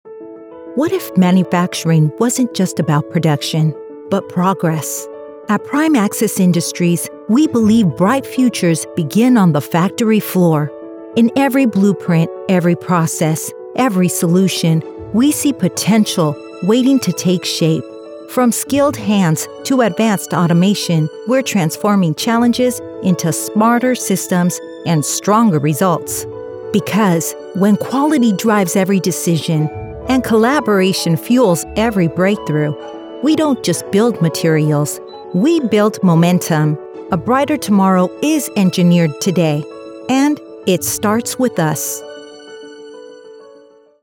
Corporate & Industrial Voice Overs
Adult (30-50)